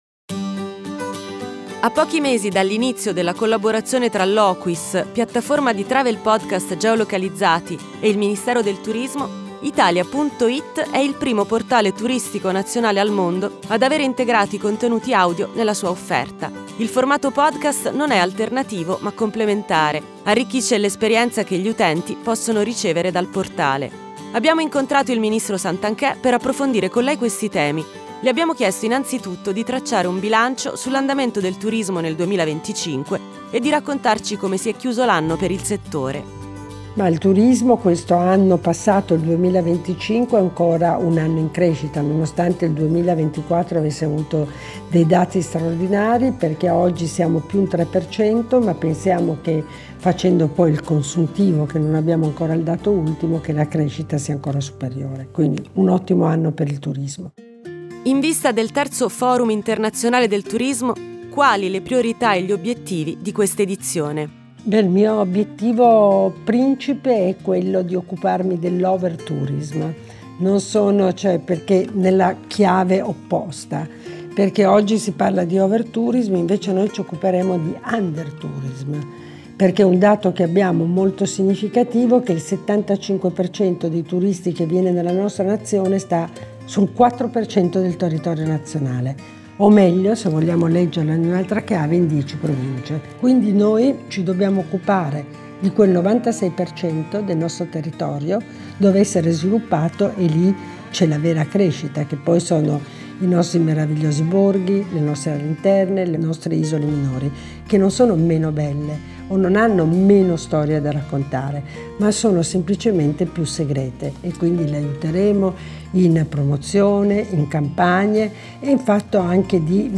Ascolta l’intervista del Ministro Santanchè qui
Intervista-Loquis-al-Ministro-Santanche_Welcome-to-Meraviglia.mp3